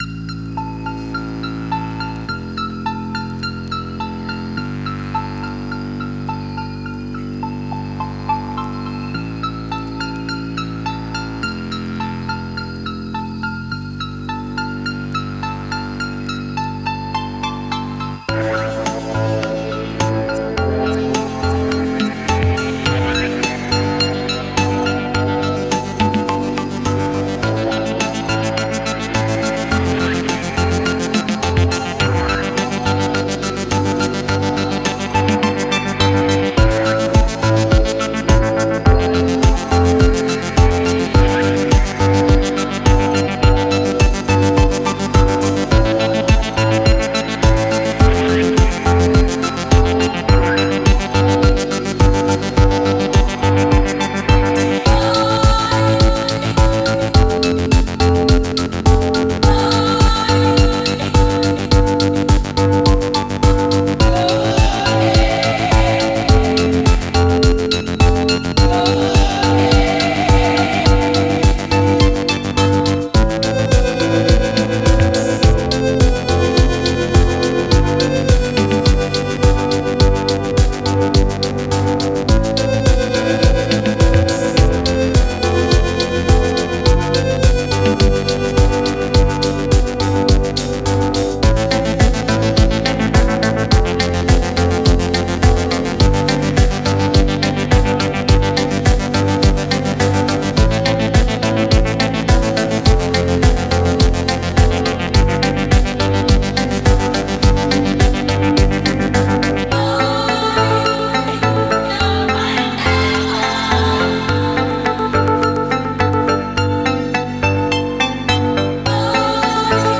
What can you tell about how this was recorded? Not mixed Rough mix